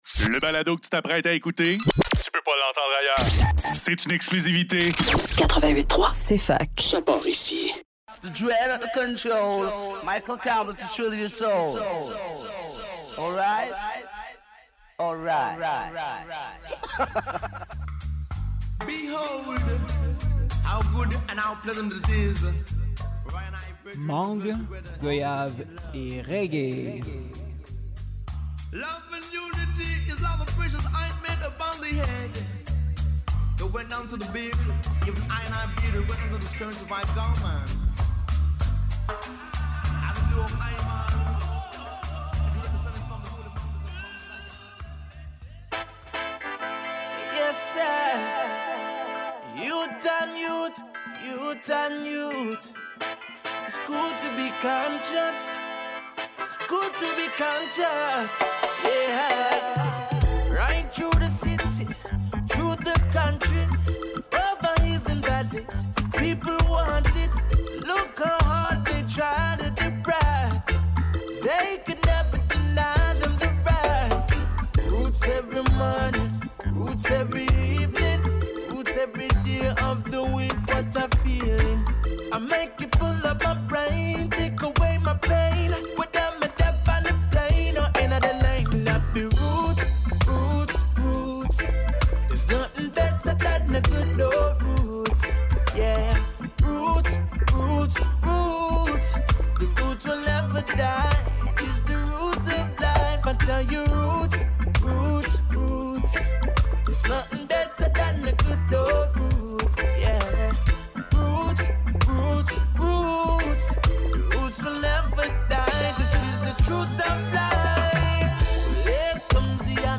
CETTE SEMAINE: Reggae contemporain Ce soir c’est du roots contemporain. Plus de la moitié des pièces proviennent de recherches récentes.